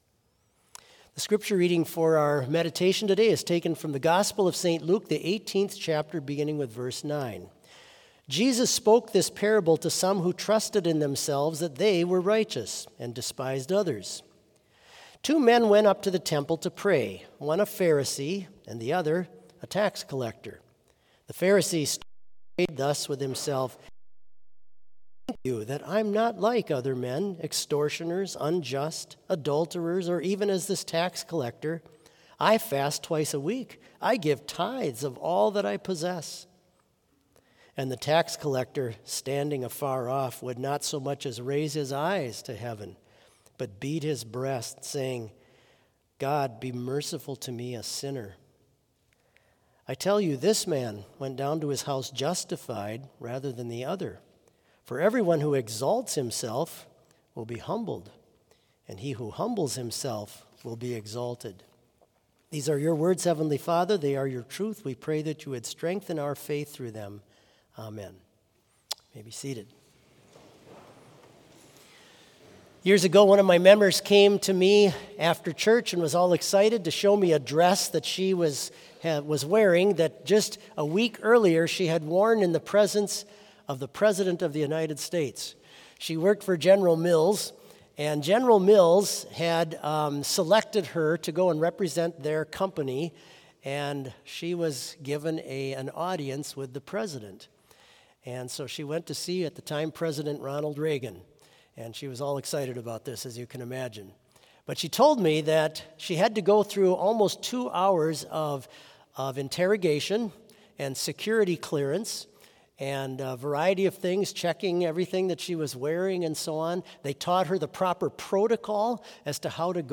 Complete service audio for Tuesday Chapel - August 22, 2023